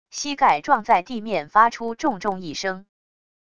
膝盖撞在地面发出重重一声wav音频